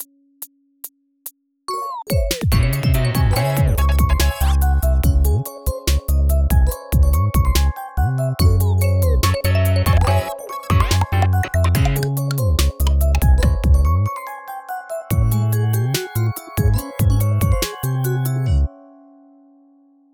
inst（カラオケ）素材など
インスト音源（BPMは143）
音域はmid1C#〜hiC#(だった気がする。というかほぼセリフ)
いたって普通の女性ボーカルくらいのキーだと思います。